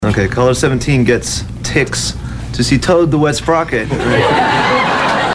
Tags: nine inch nails trent reznor broken ep lollapalooza interview